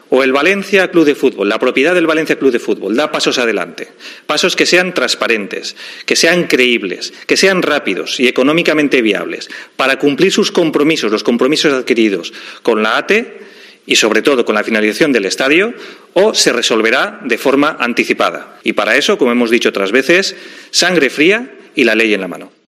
AUDIO. Así lo ve el conseller Arcadi España